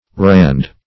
Rand \Rand\ (r[a^]nd), n. [AS. rand, rond; akin to D., Dan.,